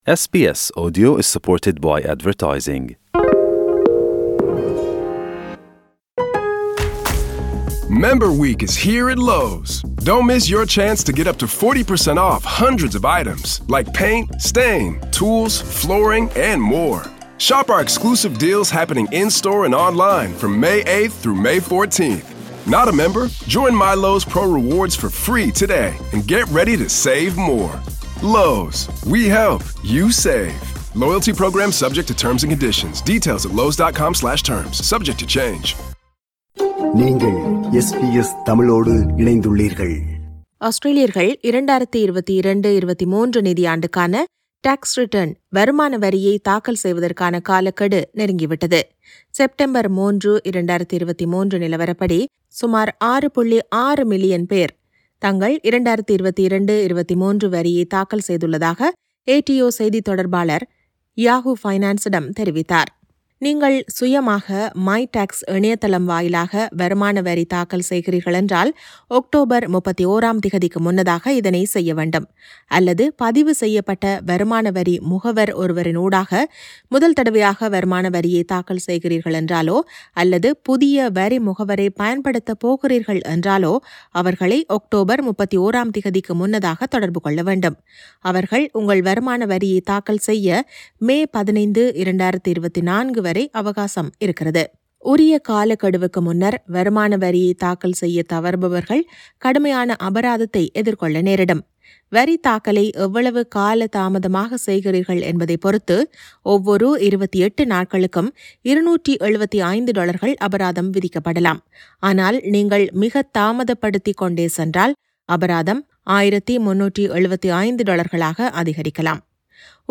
செய்தி விவரணத்தை